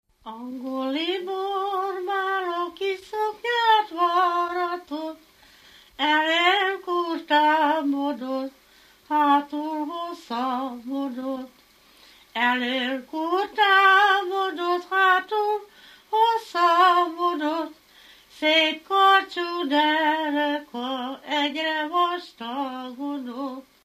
Alföld - Békés vm. - Vésztő
Műfaj: Ballada
Stílus: 7. Régies kisambitusú dallamok
Szótagszám: 6.6.6.6
Kadencia: 4 (b3) 1 1